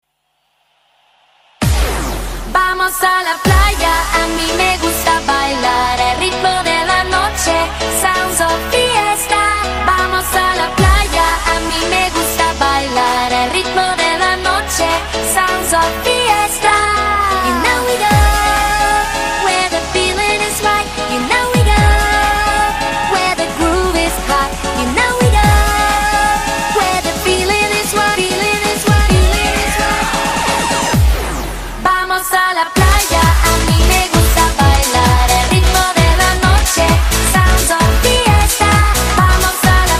танцевальные , зажигательные , испанские